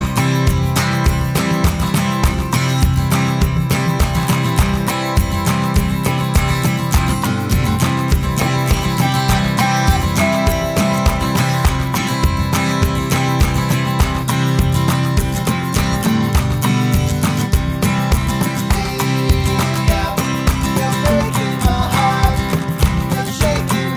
With Harmony Pop (1960s) 2:45 Buy £1.50